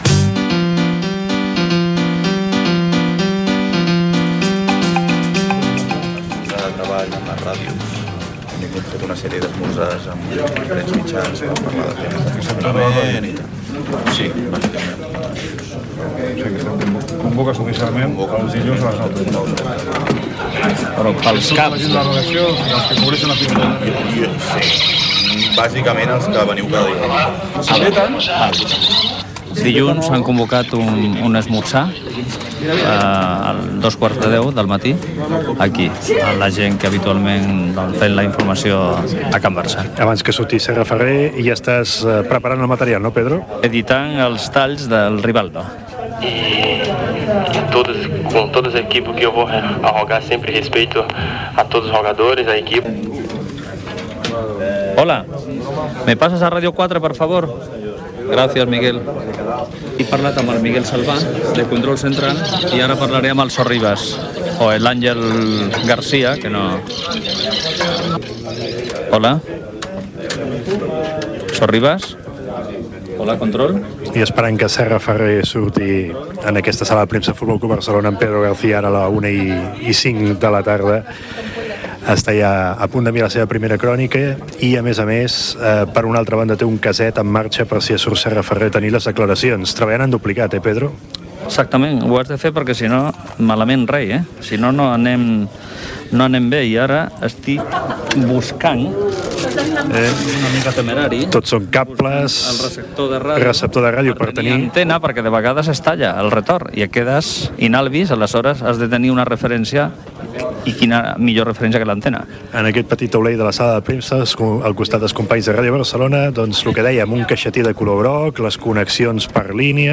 Reportatge
a les instal·lacions del Nou Camp en un dia d'entrenament de l'equip